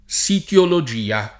vai all'elenco alfabetico delle voci ingrandisci il carattere 100% rimpicciolisci il carattere stampa invia tramite posta elettronica codividi su Facebook sitologia [ S itolo J& a ] o sitiologia [ S it L olo J& a ] s. f.